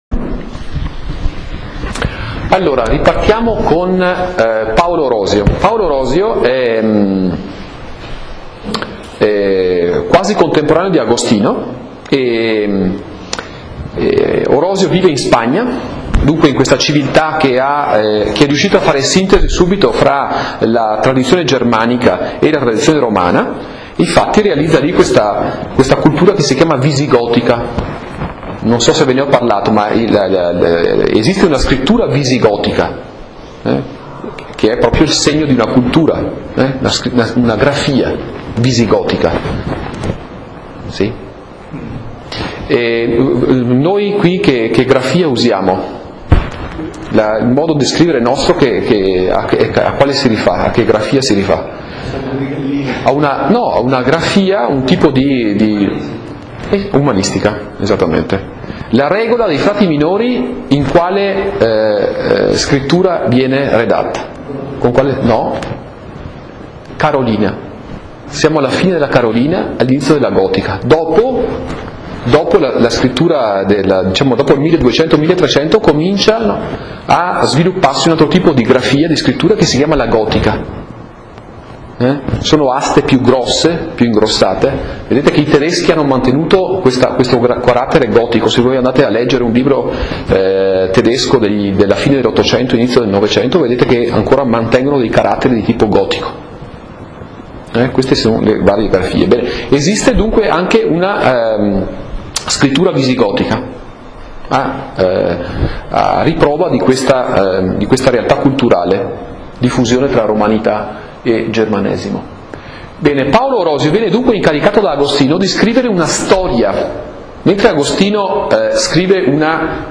In Italian: 20� lezione - 10 marzo 2010